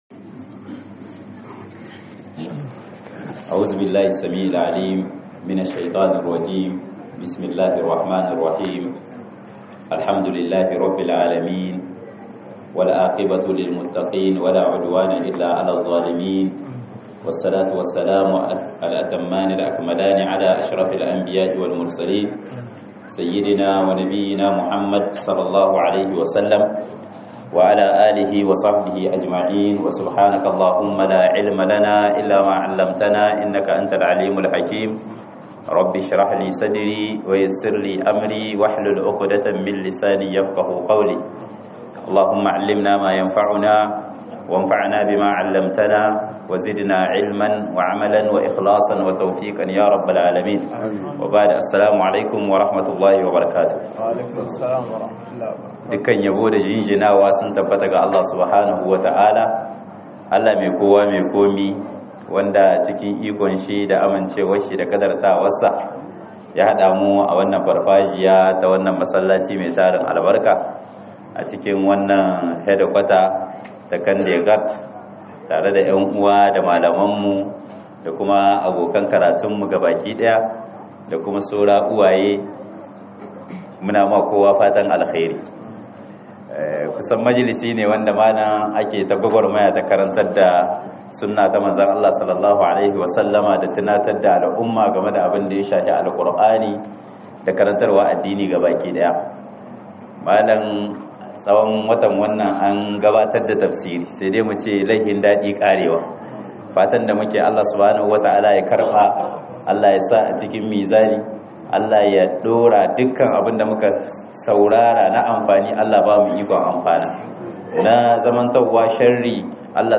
Kokarin Cigaba da Aikin Alheri a Ramadan - MUHADARA